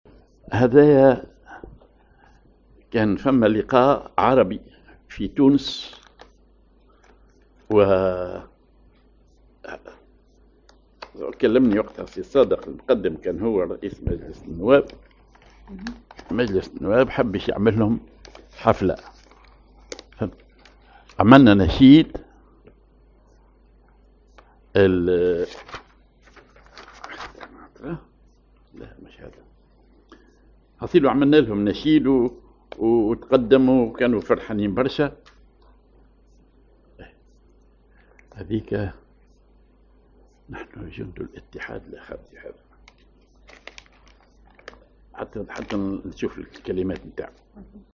أغنية